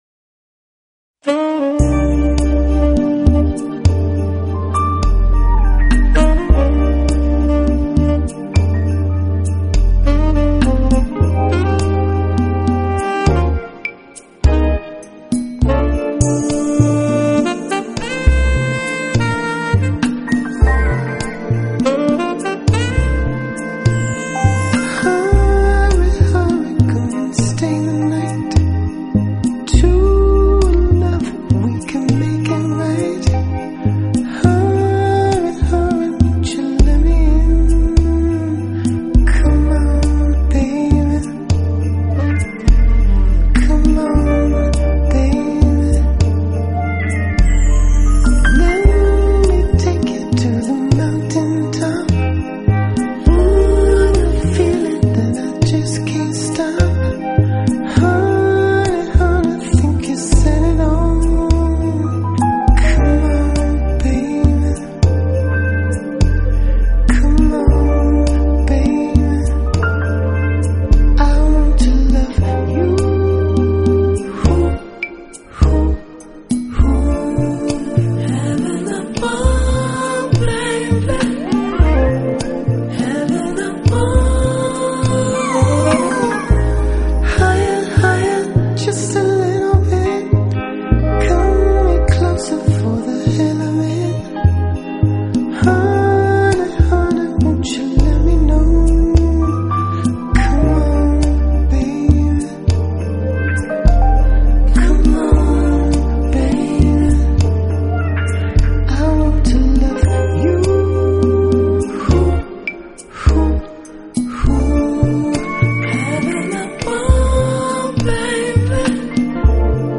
专辑风格：Contemporary Jazz / Smooth Jazz
性感而诱人，优雅的爵士乐和灵魂
歌曲着重感官的享受，旋律轻松，歌词细腻， 感情丰富奔放。